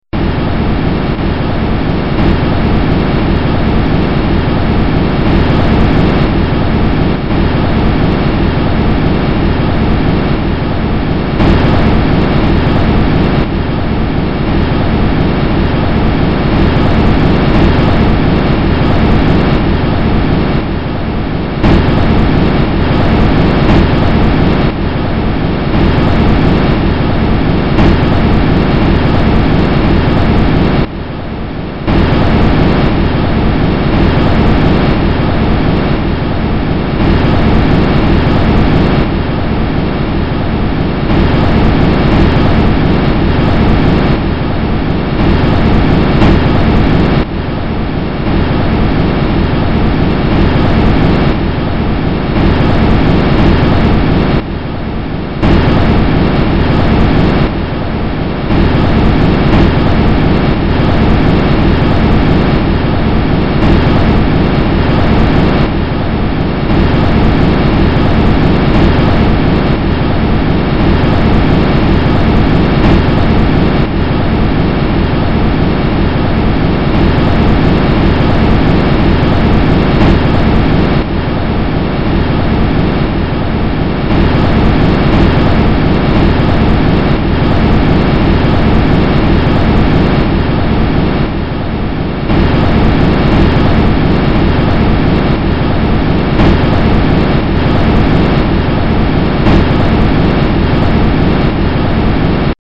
Here's the audio of the Huygens spacecraft landing on the surface of Titan.
titan_lander_descent.mp3